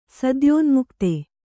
♪ sadyōnmukte